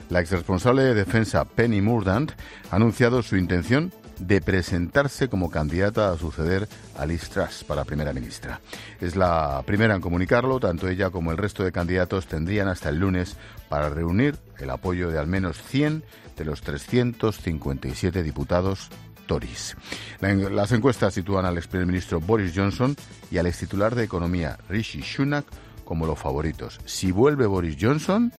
Ángel Expósito informa en 'La Linterna' sobre la primera candidata a suceder a Truss: Penny Mourdant